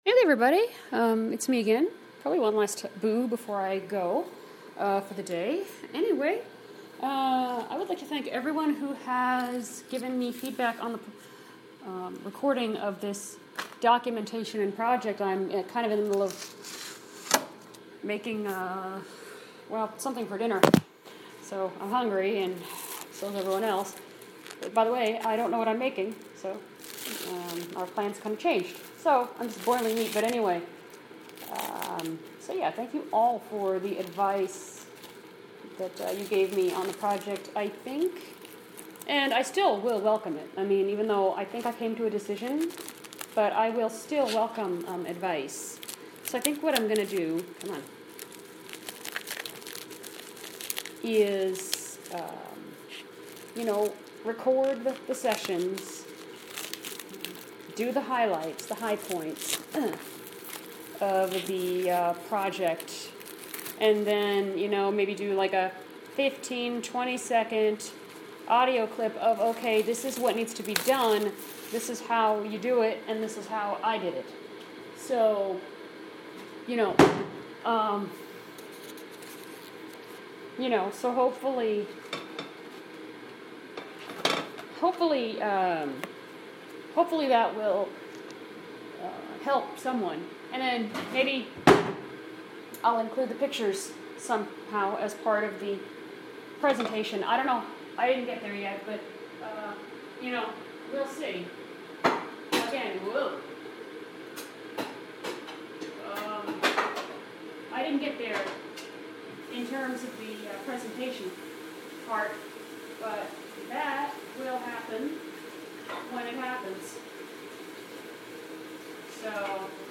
thank you so much to everyone who contributed ideas for the project. Sorry about all the rambling, I'm in the middle of preparing dinner.